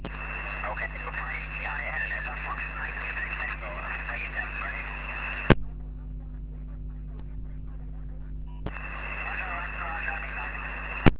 Meteor Scatter
But the activity was great, some pile-ups, QRM as usually, so I worked about 100 QSOs, all random SSB.
Used rig: TCVR R2CW, PA 500W, ant: 4x9el (North-East), 4x4el (West) and 4x4el (South).